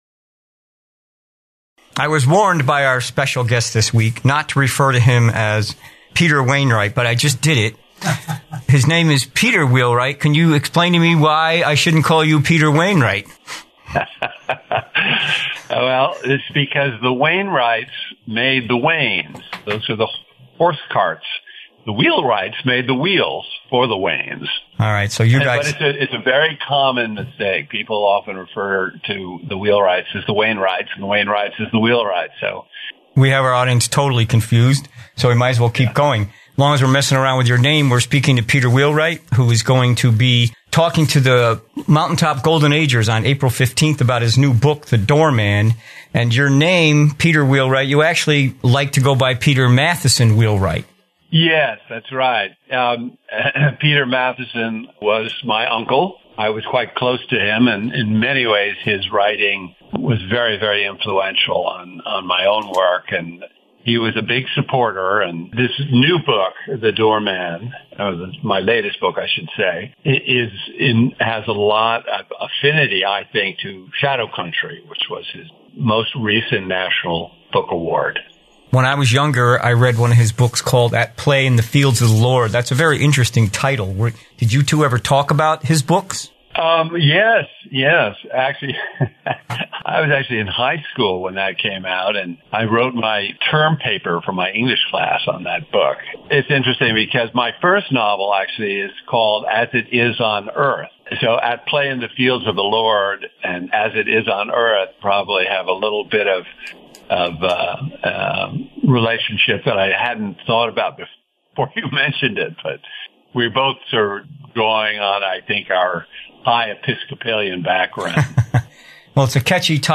04.03.24 – Live Interview on Catskill radio WRIP (named for Rip Van Winkle) about the flooded town of Gilboa, The Door-Man and writing in general.